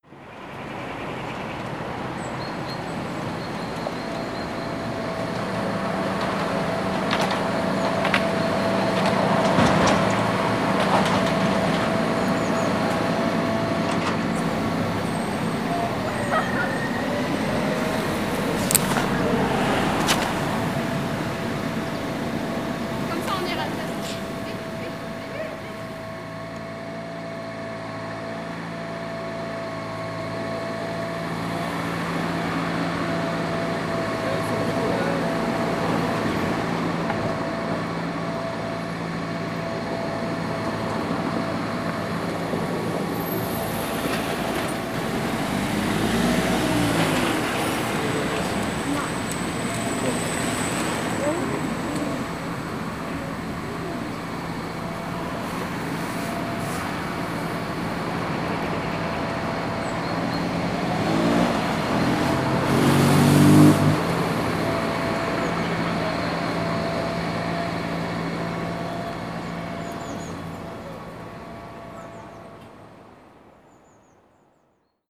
Accueil > Champs-sur-Marne (automne) > L’urbanophage
Des vagues de bitume déferlent, sont ingurgitées par une étrange machine à engrenages qui absorbe les routes, les feux tricolores, les plaques d’immatriculation, les chants d’oiseaux, les grillages, le monde, centimètre par centimètre, décibel par décibel. La machine enfle et ronfle, de plus en plus, de pire en pire, à mesure que la ville rétrécit. Bientôt elle n’est plus, mais ses morceaux épars s’agitent, s’emmêlent et tintinnabulent dans une bulle gigantesque prête à éclater en silence.